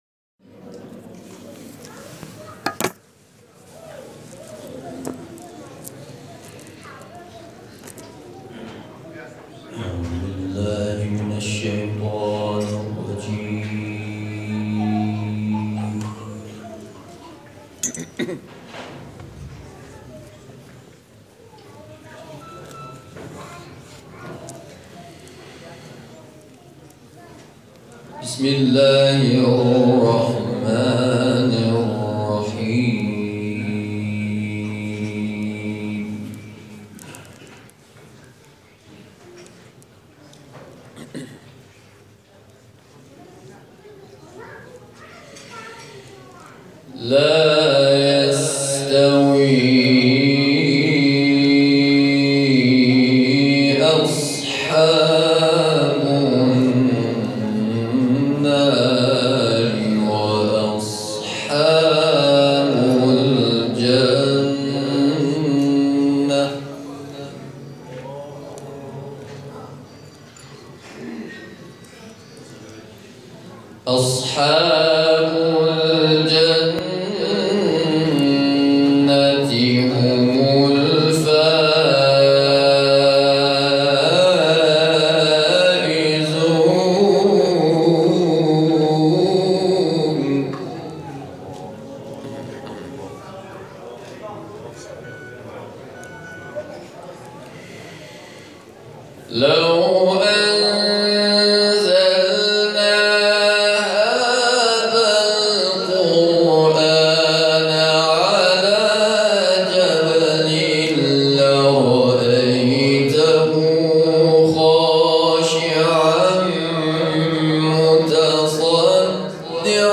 تلاوت آیاتی از سوره «شمس» و «حشر»